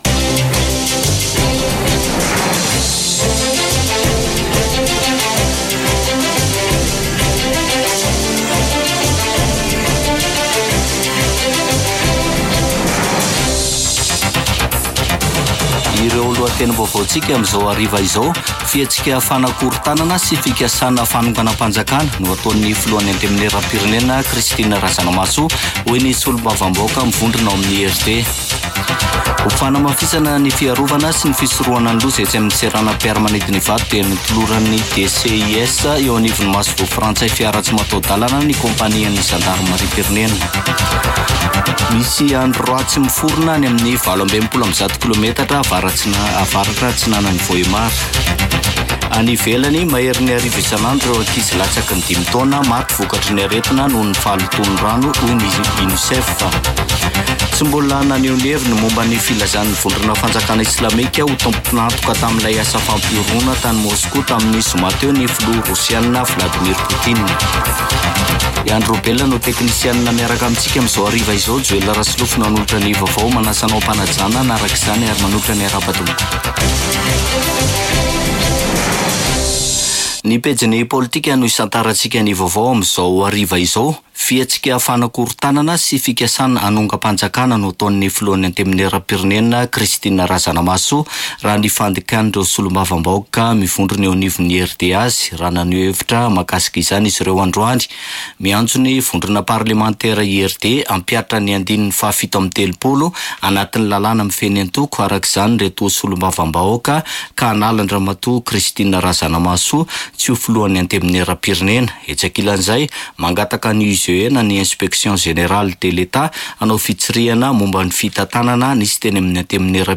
[Vaovao hariva] Alatsinainy 25 marsa 2024